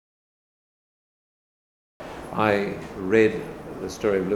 Brief excerpt of recorded interview.